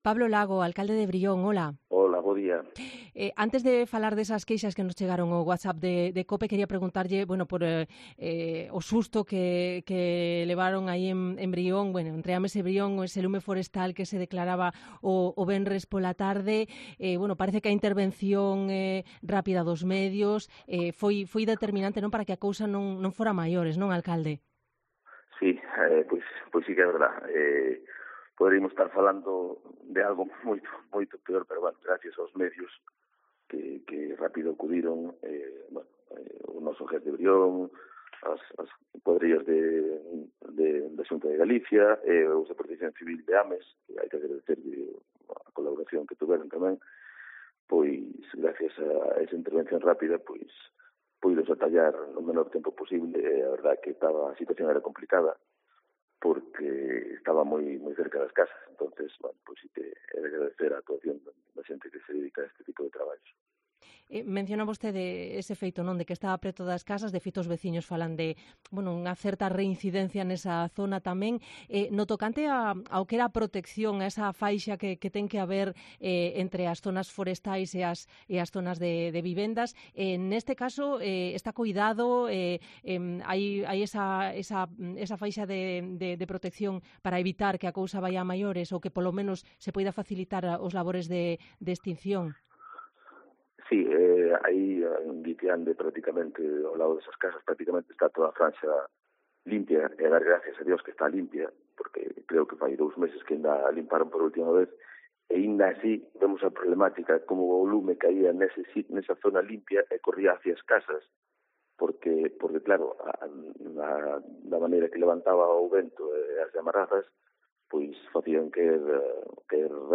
Entrevista con el Alcalde de Brión, Pablo Lago